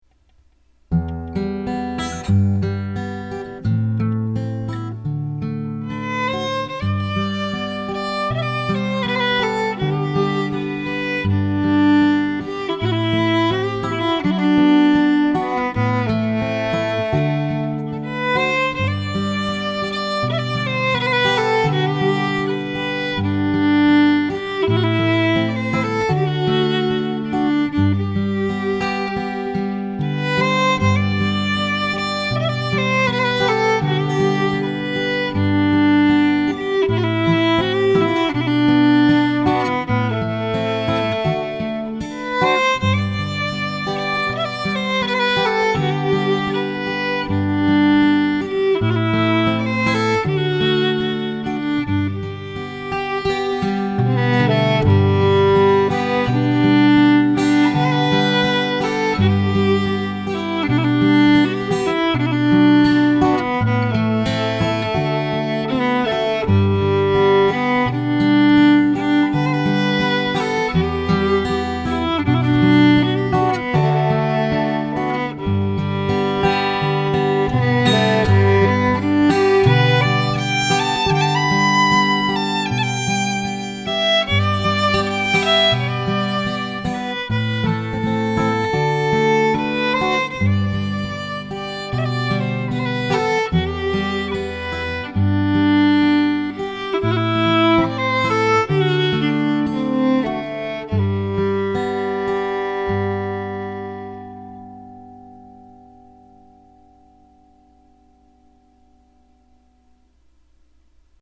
INTERMEDIATE, STRING QUARTET
Notes: grace notes
Key: G major